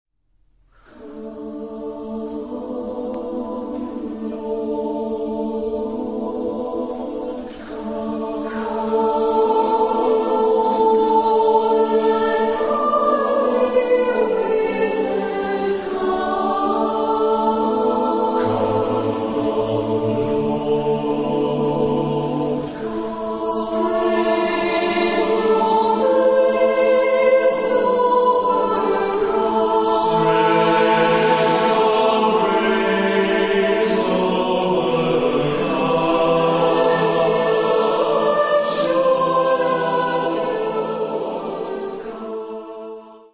Genre-Style-Form: Sacred ; Prayer ; Dance
Mood of the piece: fast ; contrasted ; rhythmic
Type of Choir: SATB  (4 mixed voices )
Instrumentation: Brass  (5 instrumental part(s))
Tonality: D major